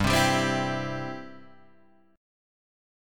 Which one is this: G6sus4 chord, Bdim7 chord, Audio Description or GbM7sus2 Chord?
G6sus4 chord